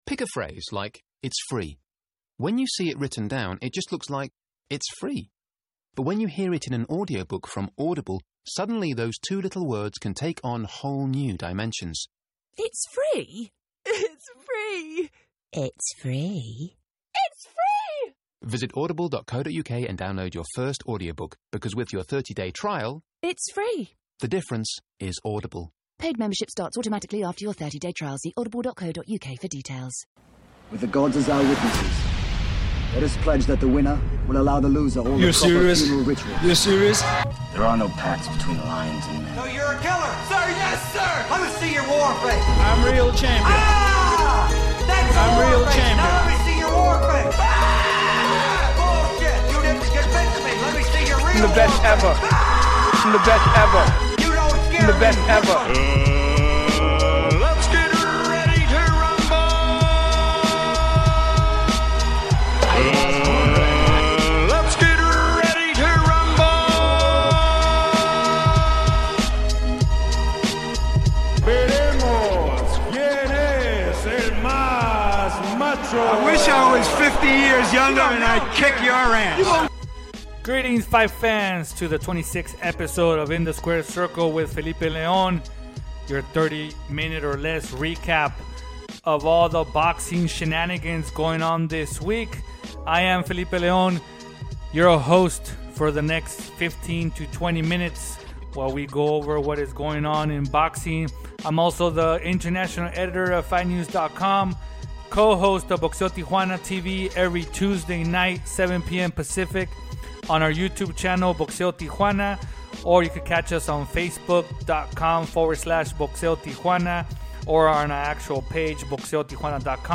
passes the latest news in the sport with a fast pace style of 30 minutes or less